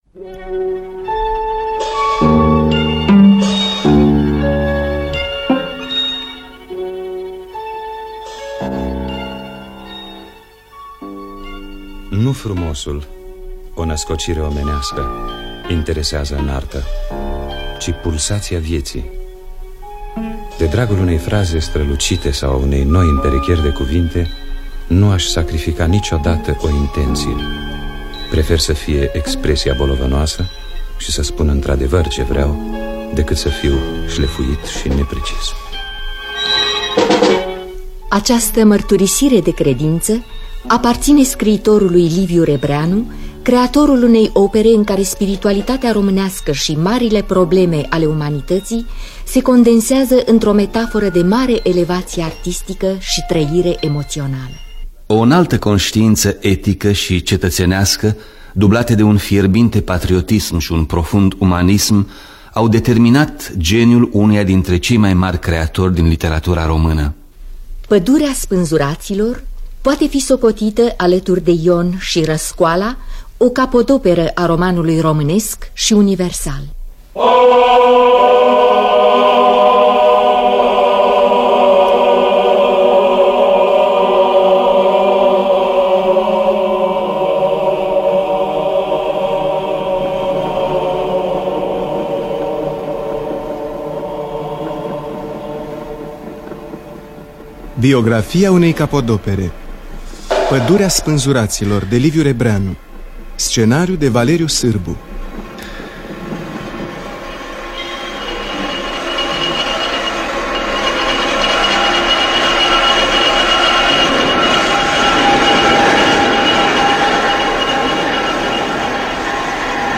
Biografii, Memorii: Liviu Rebreanu – Padurea Spanzuratilor (1974) – Teatru Radiofonic Online